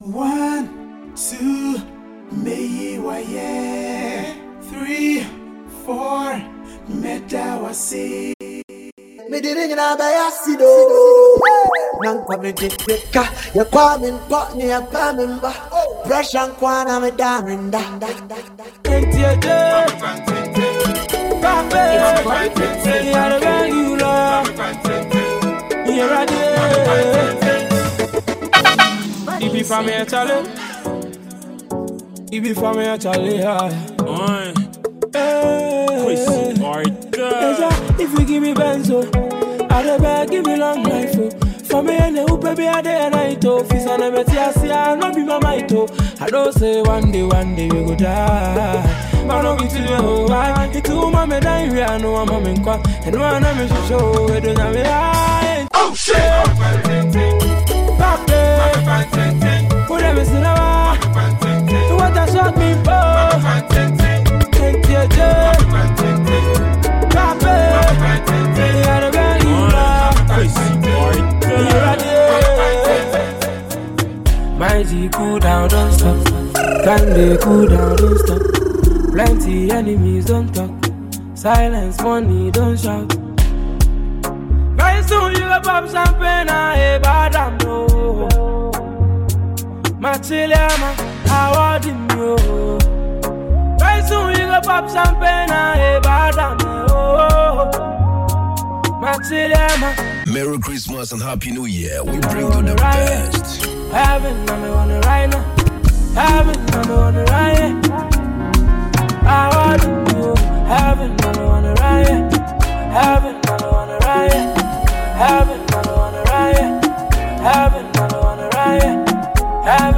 signature style and expert blending